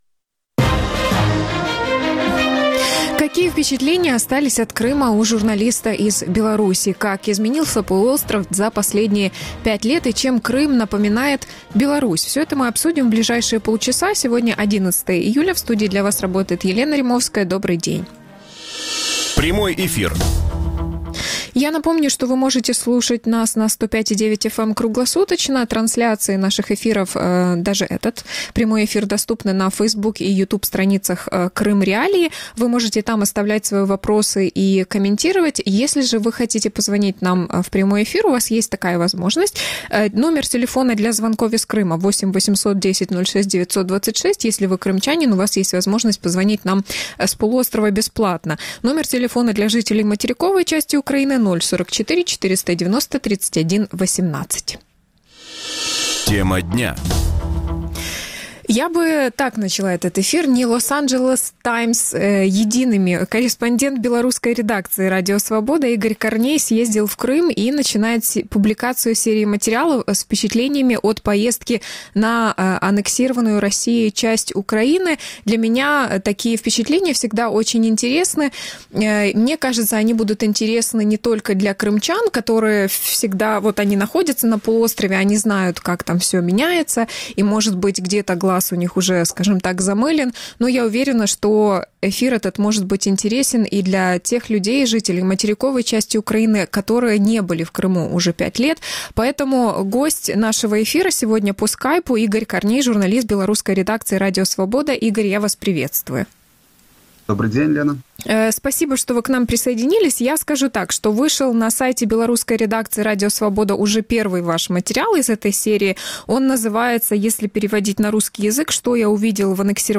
Гость эфира